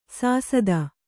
♪ sāsada